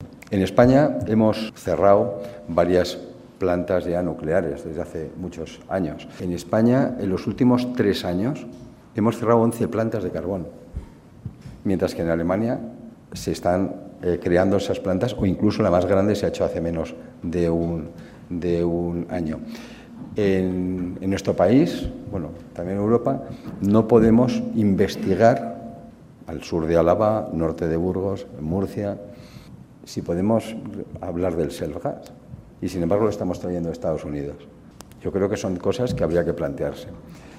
El presidente de la CEOE, Confederación Española de Organizaciones Empresariales, Antonio Garamendi, ha participado en un foro económico celebrado en Vitoria-Gasteiz